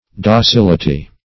Docility \Do*cil"i*ty\, n. [L. docilitas, fr. docilis: cf. F.